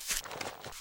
Divergent / mods / Footsies / gamedata / sounds / material / human / step / grass4.ogg
grass4.ogg